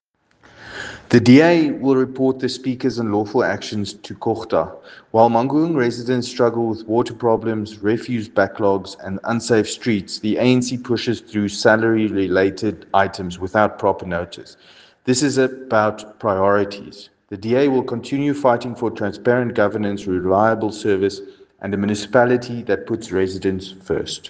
Afrikaans soundbites by Cllr Paul Kotzé and